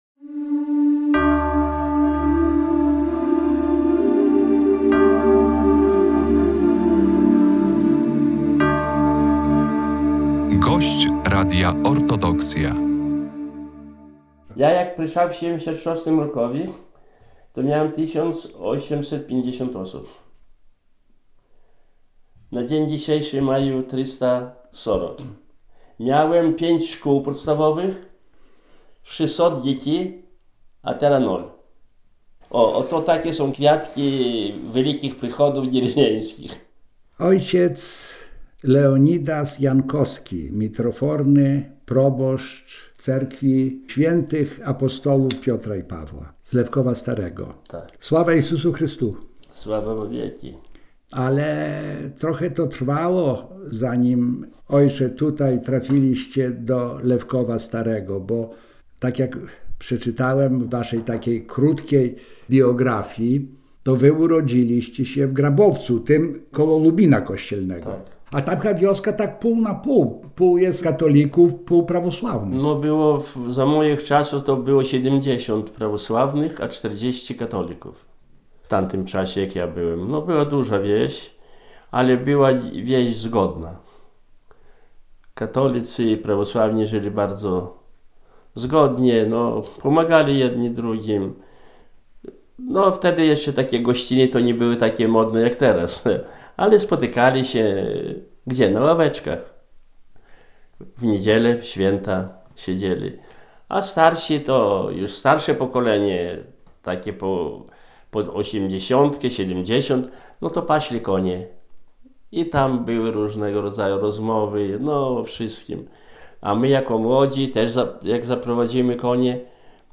A kiedyś to było… rozmowy o życiu i Cerkwi – to cykl audycji radiowych z prawosławnymi duchownymi, którzy tworzyli historię Polskiego Autokefalicznego Kościoła Prawosławnego.